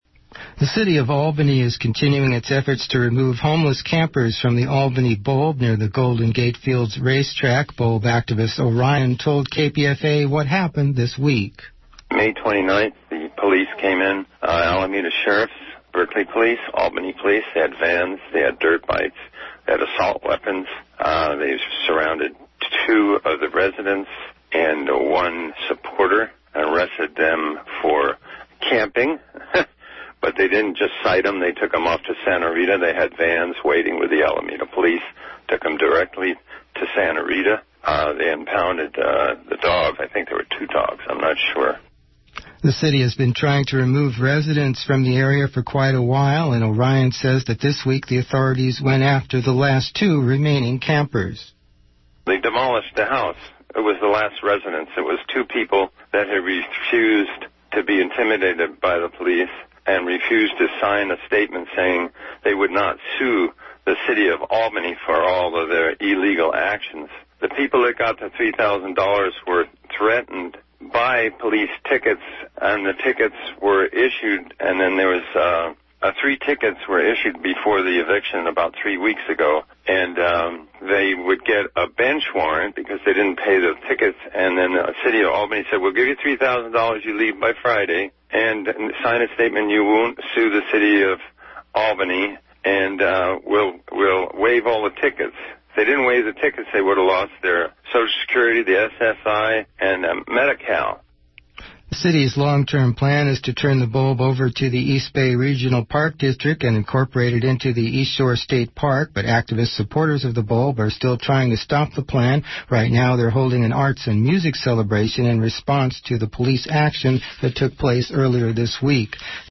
KPFA News interview